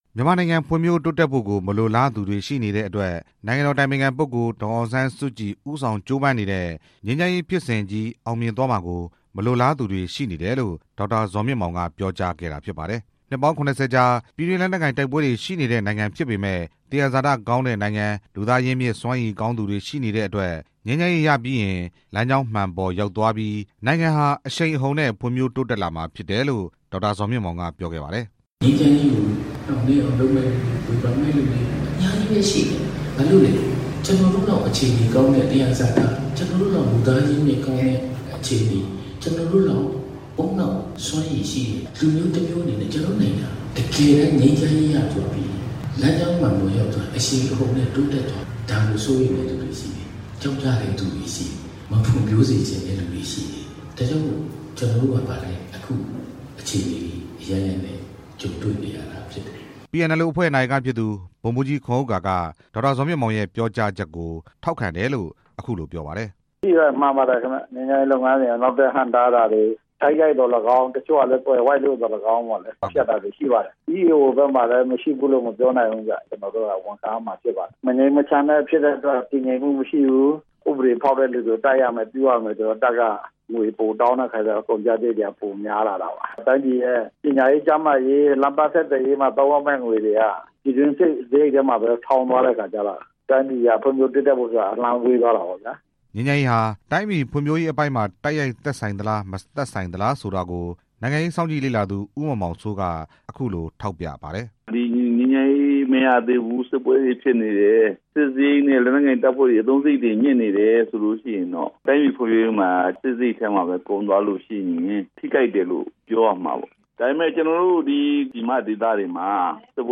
မြန်မာပြည်ငြိမ်းချမ်းရေးကို နှောင့်နှေးကြန့်ကြာအောင်လုပ်နေသူတွေ ၊ ဝေဖန်တဲ့သူတွေရှိနေပြီး နိုင်ငံဖွံ့ ဖြိုးတိုးတက်သွားမှာကို စိုးရိမ်နေကြသူတွေရှိနေတယ်လို့ မန္တလေး တိုင်းဒေသကြီးဝန်ကြီးချုပ် ဒေါက်တာ ဇော်မြင့်မောင်က ဒီကနေ့ မိုးကုတ်မြို့ဒေသခံတွေနဲ့ တွေ့ဆုံတဲ့ အခမ်းအနားမှာ ပြောကြားလိုက်ပါတယ်။